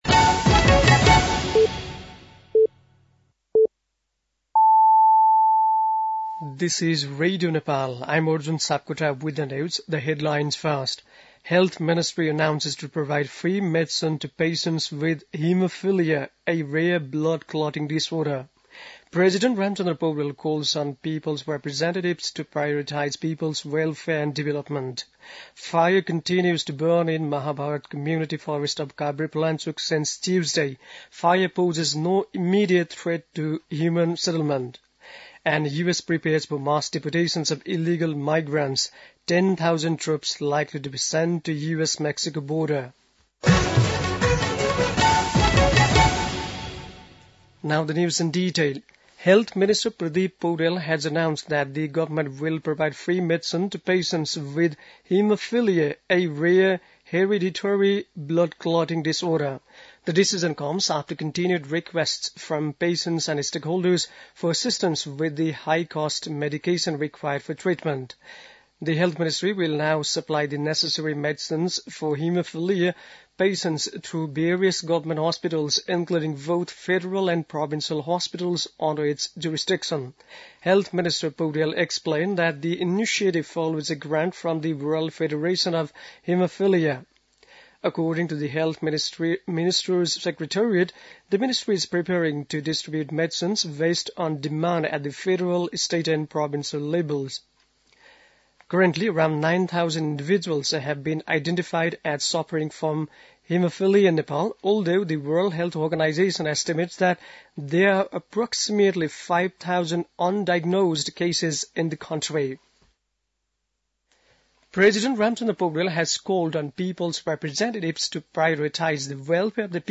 बेलुकी ८ बजेको अङ्ग्रेजी समाचार : ११ माघ , २०८१
8-pm-english-news-10-10.mp3